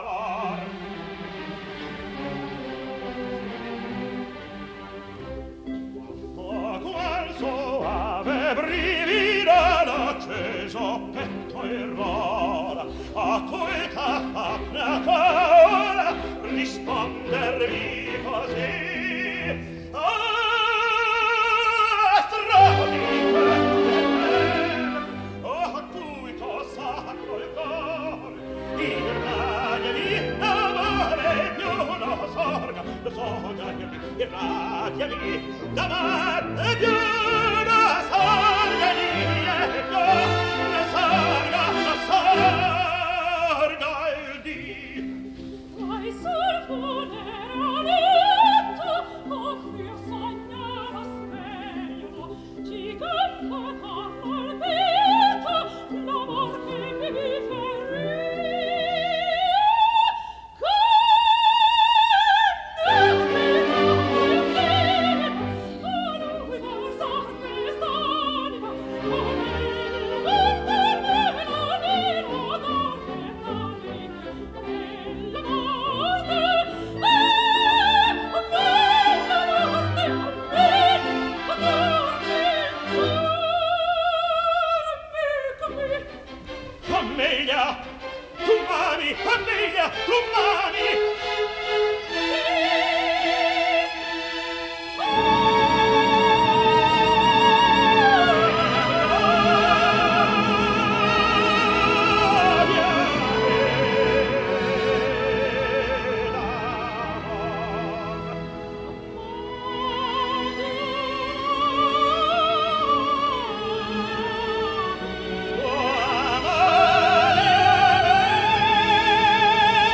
Запись 10 декабря 1955 года, Metropolitan Opera.